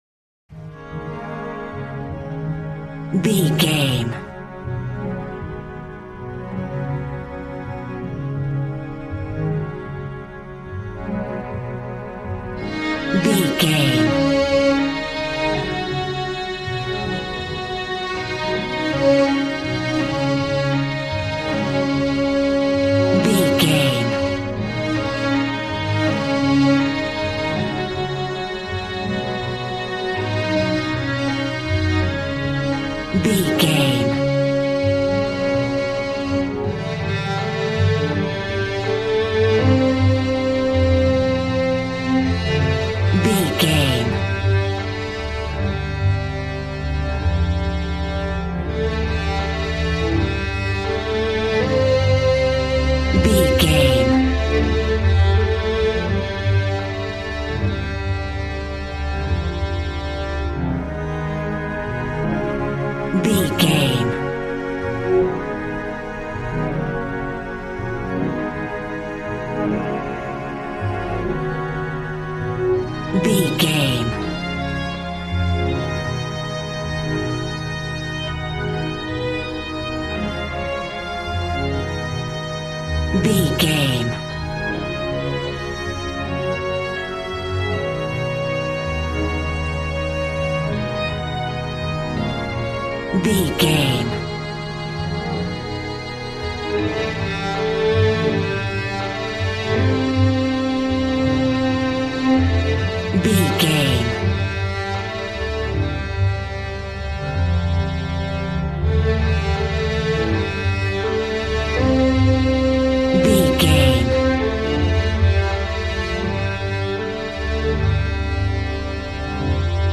Ionian/Major
D♭
joyful
conga